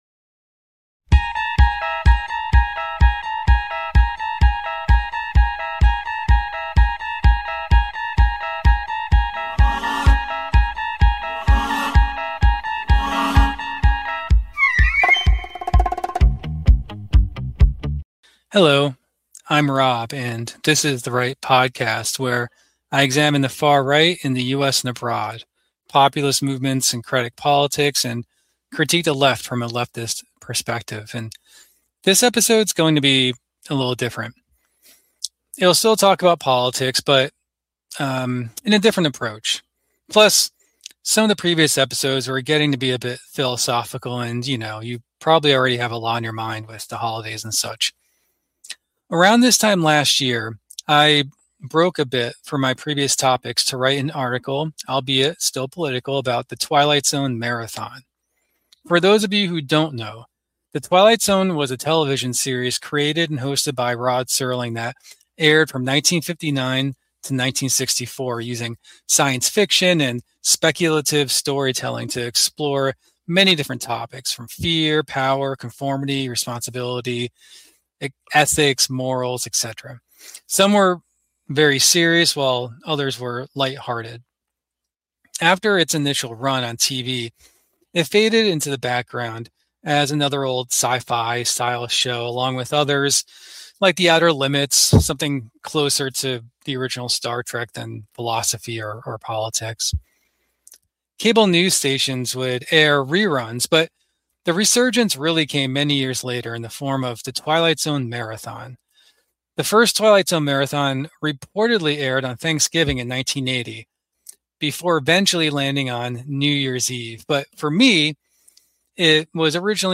Topics range from the 1990's Patriot Movement in the US to Eastern European extremist ideologies. Be aware that these are the audio from YouTube videos.